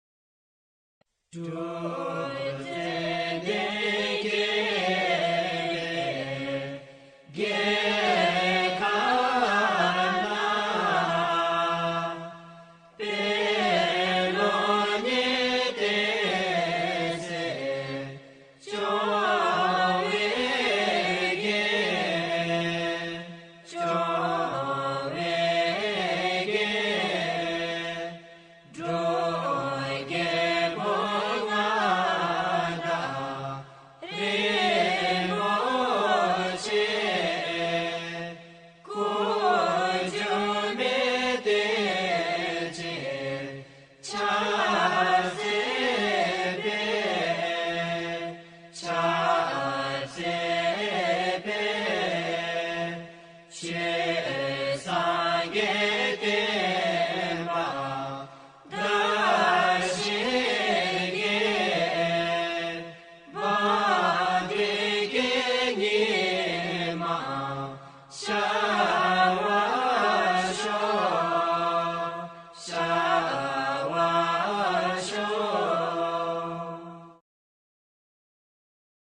Bhutanese_anthem.mp3